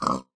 mob / pig3